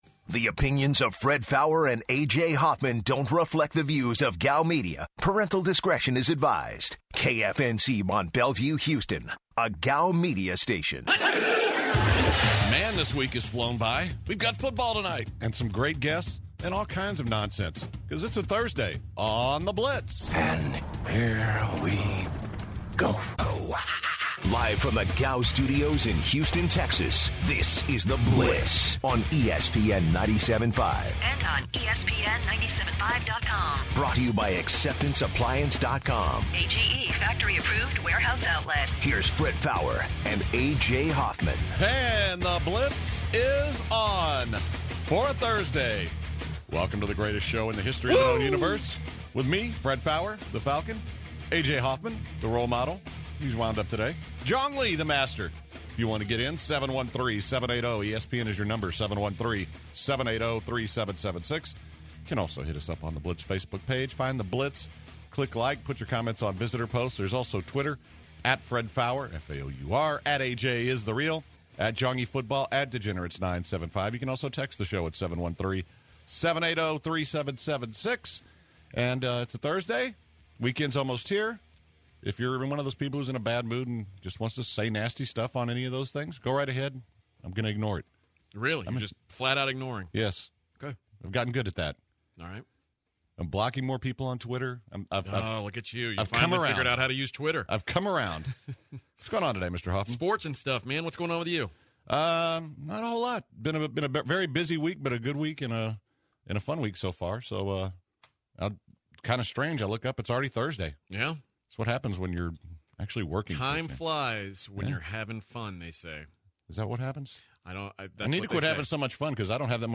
UFC Light Weight Sage Northcutt came in studio for an interview. The hour ended with some conversations about the Astros off season moves and the recent College Football coaching changes.